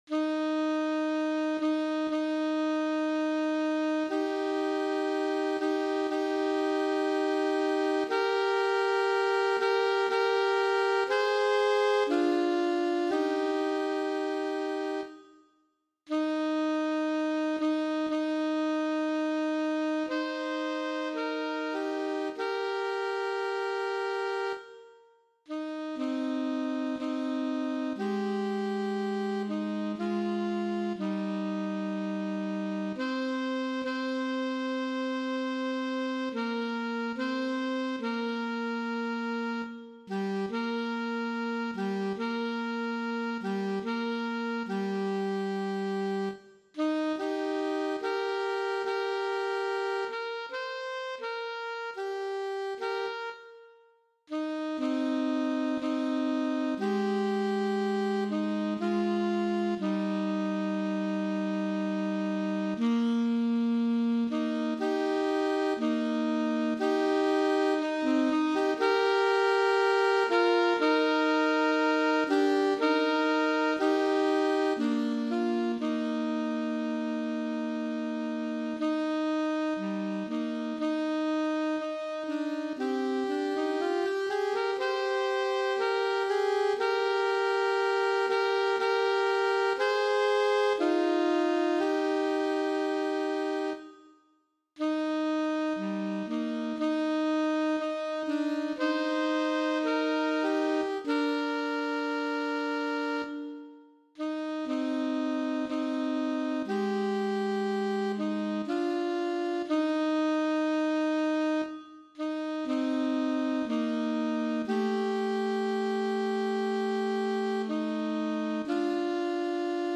• Easy-Medium